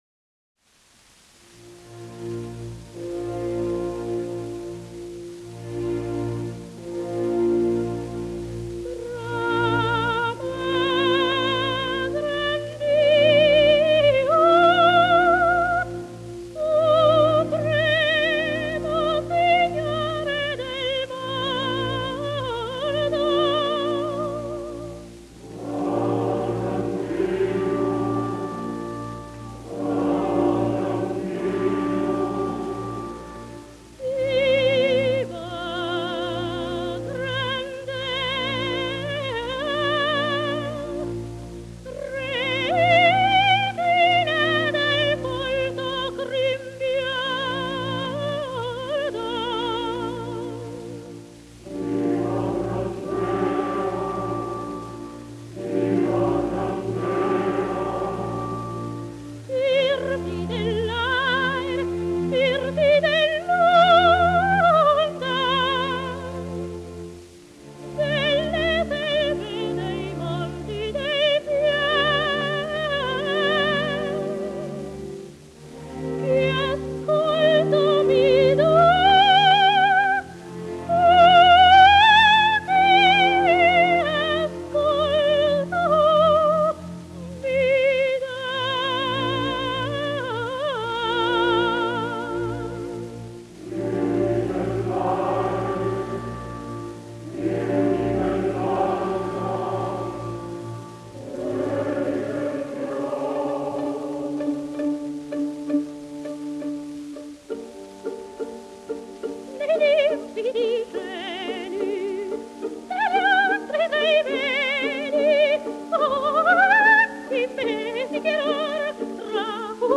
ДАЛЬ MОHTE (Dal Monte) Тоти (наст. имя и фамилия - Антониетта Mенегелли, Meneghelli) (27 VI 1893, Мольяно-Венето - 26 I 1975) - итал. певица (колоратурное сопрано).
Редкий по красоте тембра, гибкости и яркости звучания голос, виртуозное вок. мастерство, музыкальность и артистич. дарование поставили Д. М. в ряд выдающихся оперных певиц мира.